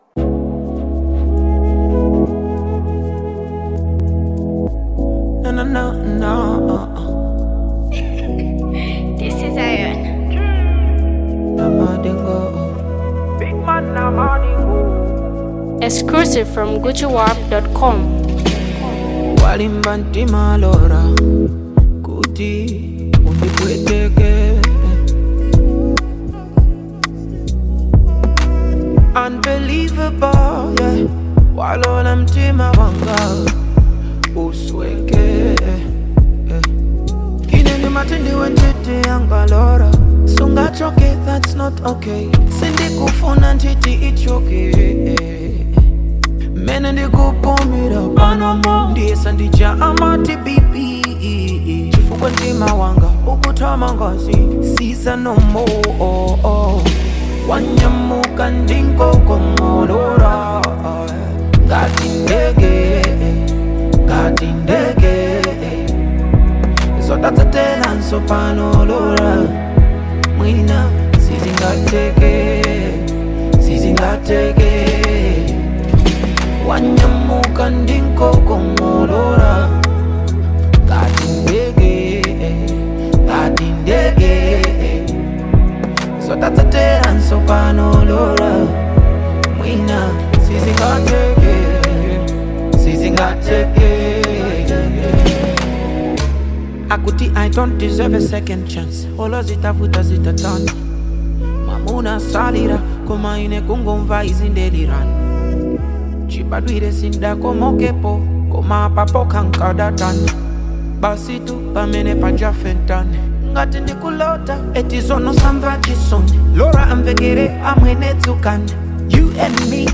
soul-stirring melody
soothing rhythms